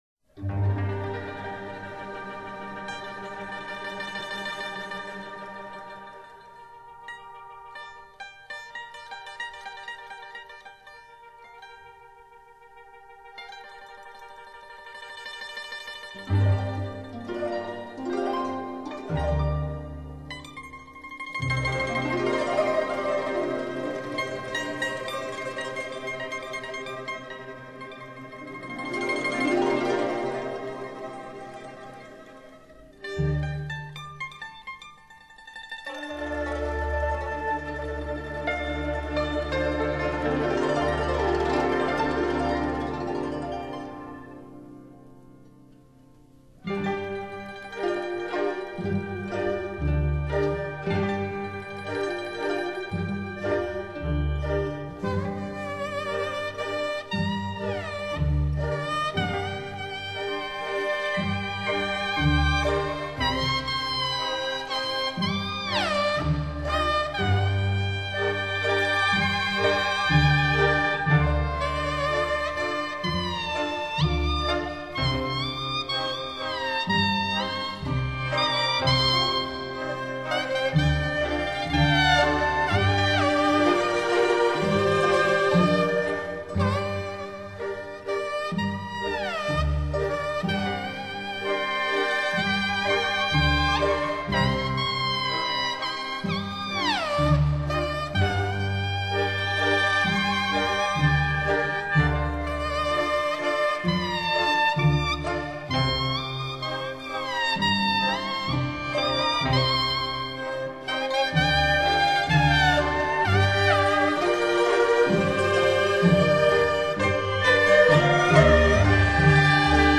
化，欣赏本专辑的曲目，可以感受到不同民族鲜明的特色和浓郁的生活气息。
树叶独奏